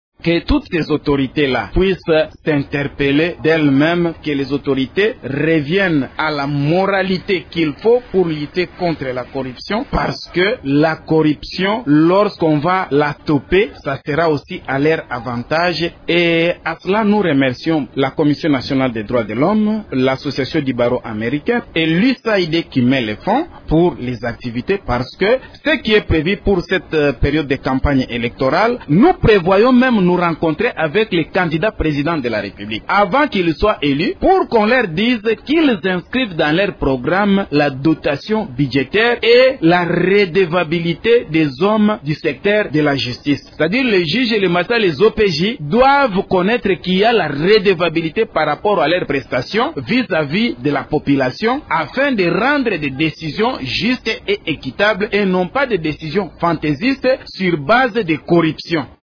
« Que toutes ces autorités puissent s’interpeller elles –mêmes, [qu’elles] reviennent à la moralité qu’il faut pour lutter contre la corruption », a-t-il poursuivi dans cet extrait son sonore :